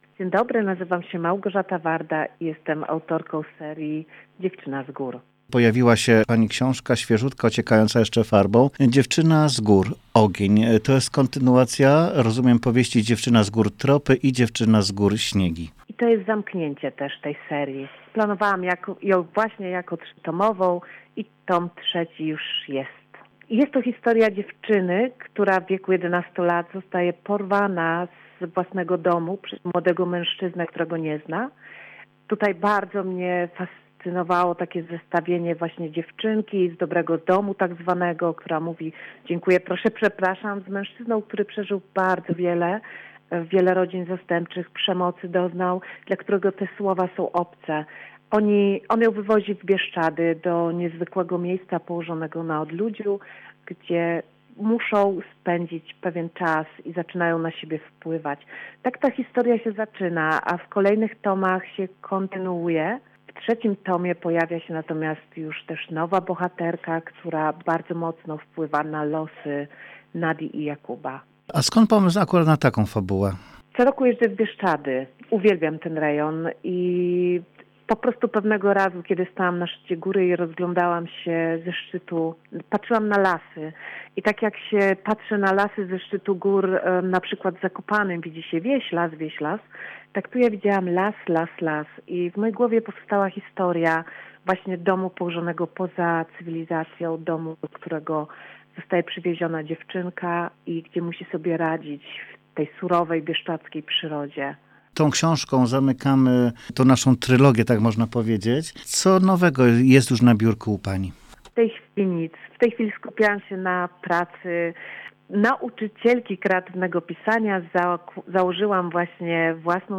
dziewczyna-z-gor-rozmowa.mp3